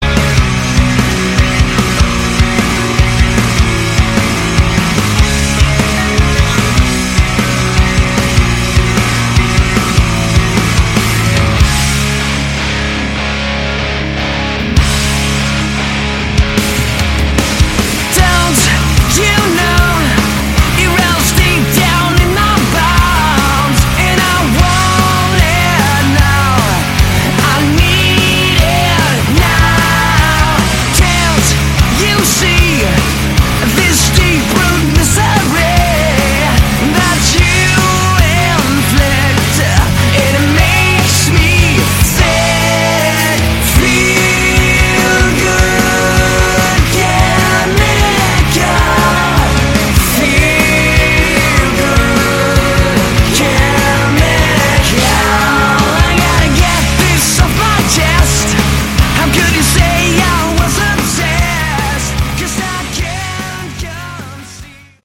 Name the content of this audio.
Category: Sleaze Glam